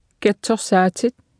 Below you can try out the text-to-speech system Martha.
Speech synthesis Martha to computer or mobile phone
Speech Synthesis Martha